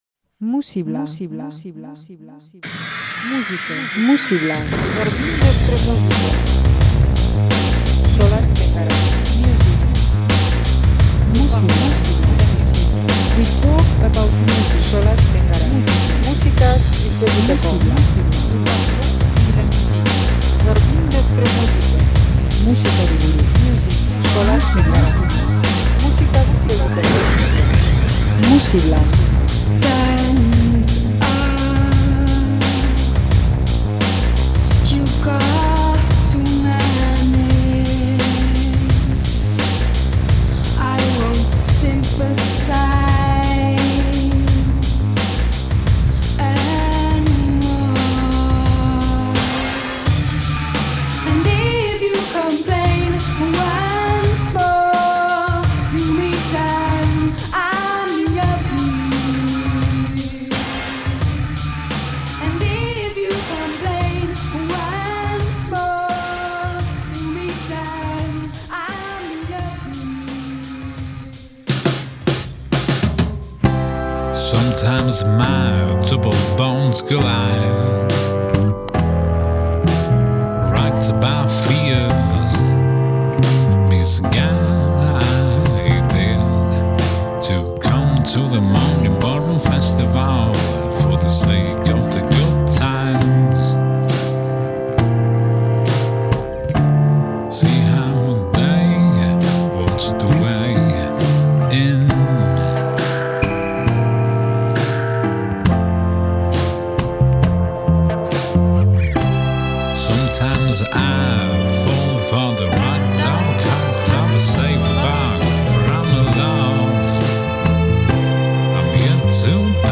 pop retroa eta sofistikatua
Rock freskoa
Soinu retroak eta giro lanbrotsuak.